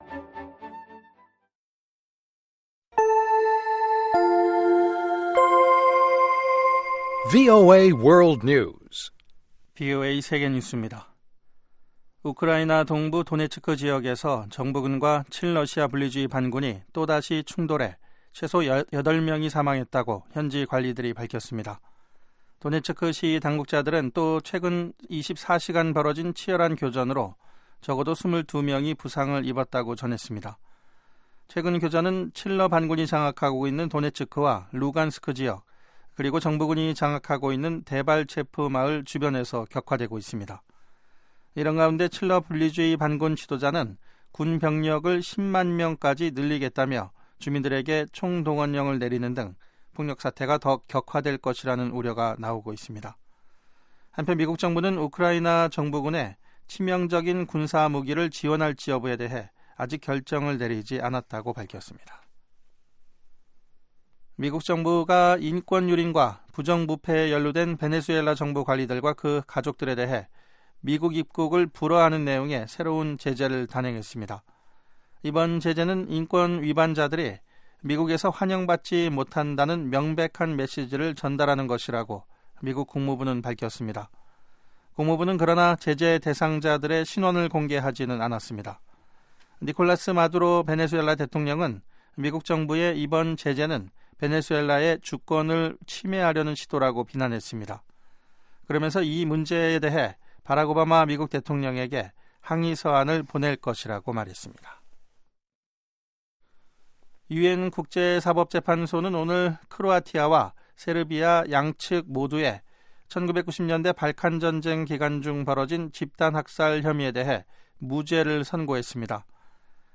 VOA 한국어 방송의 간판 뉴스 프로그램 '뉴스 투데이' 3부입니다. 한반도 시간 매일 오후 11시부터 자정까지 방송됩니다.